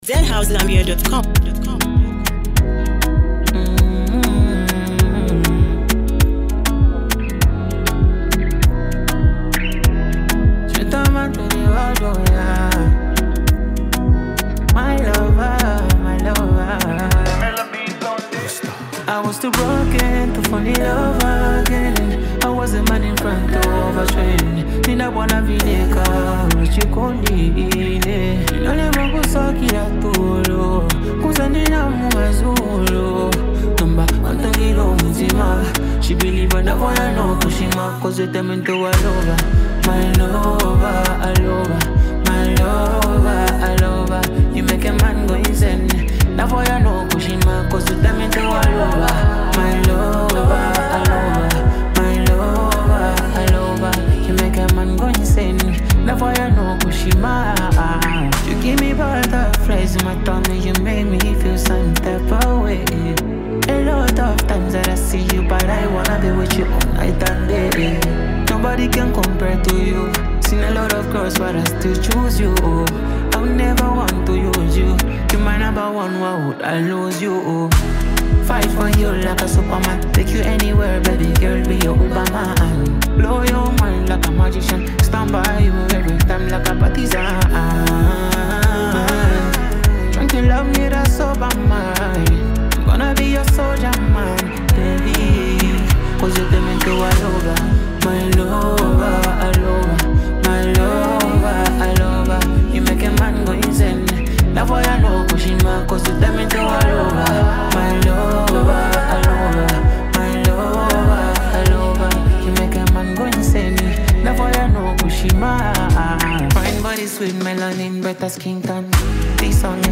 a soulful masterpiece packed with passion and melody.